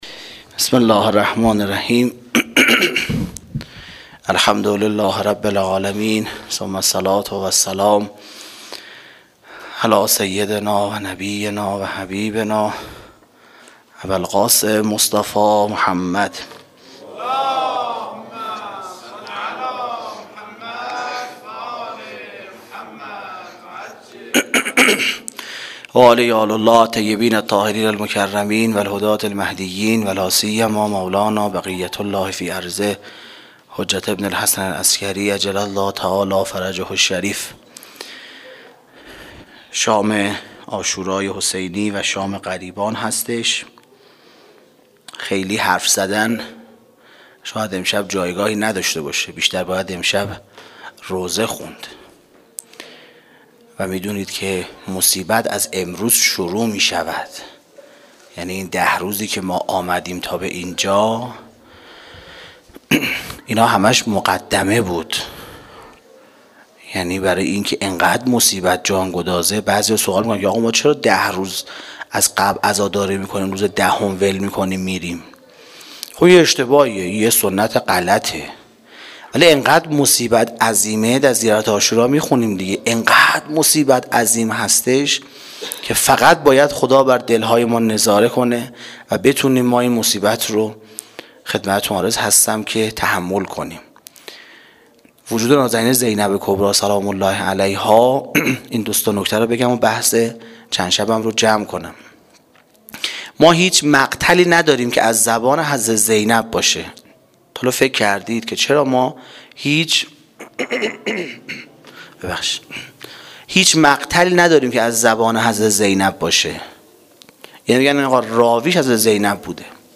سخنرانی شب یازدهم محرم(شام غریبان)
Sokhanrani-Shabe-11-moharram94.mp3